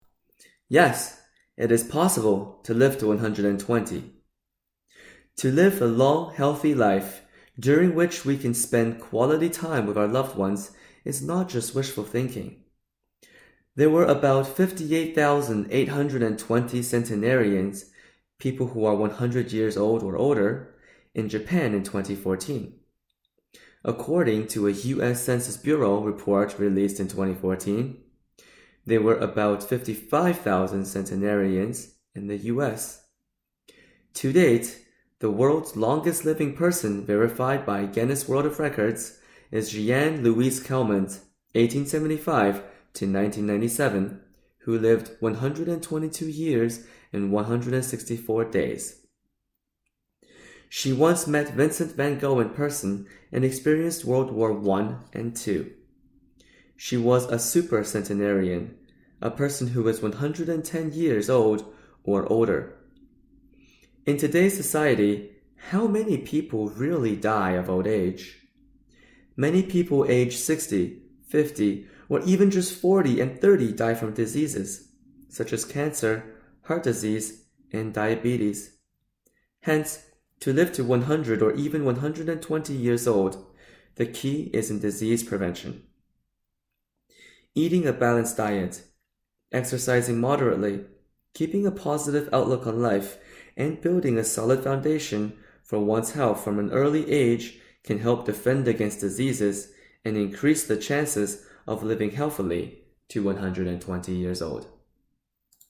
Audiobook Nutritional Immunology Health knowledge pieces